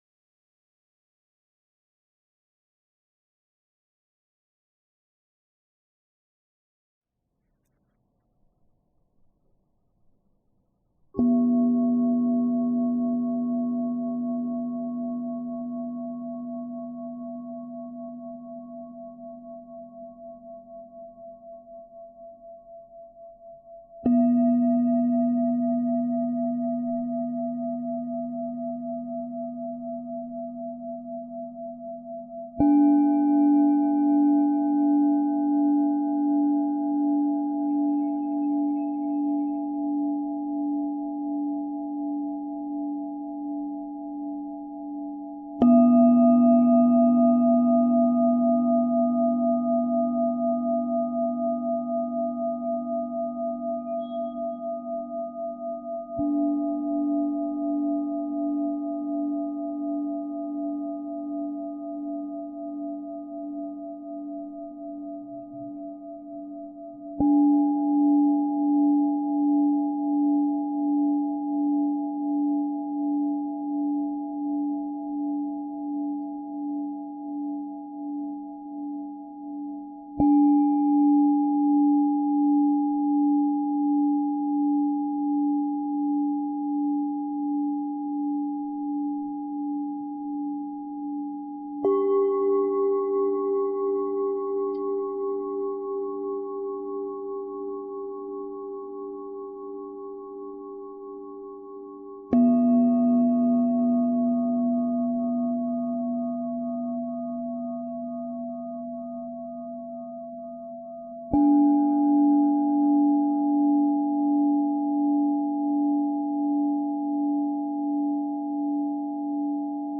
梵音30分鐘打坐冥想.mp3